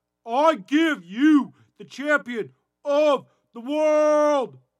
怪异的短唱节奏 120 Bpm
描述：今天是怪异人声循环日xD ......用我的麦克风唱一些东西，再加上一些效果，你就有了一个拍摄效果......很想听听你能用这个做什么，但我认为它没有用...xD
Tag: 120 bpm Weird Loops Fx Loops 516.84 KB wav Key : Unknown